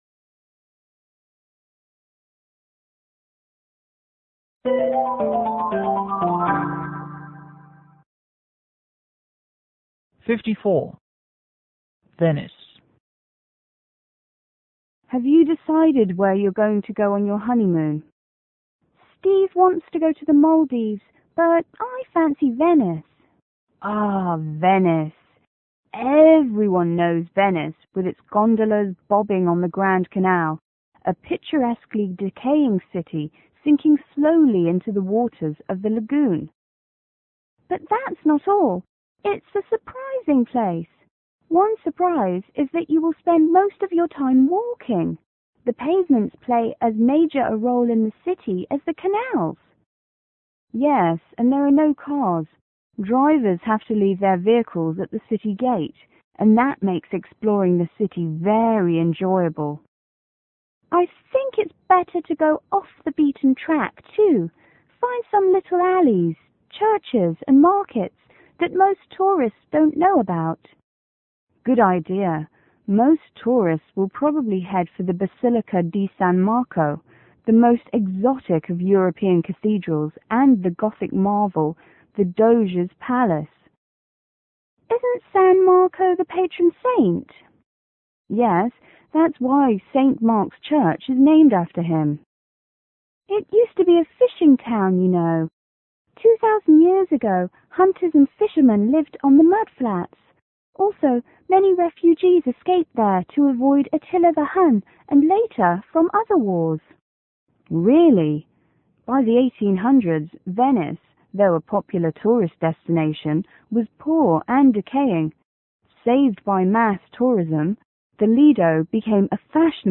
W1: Woman 1        W2: Woman 2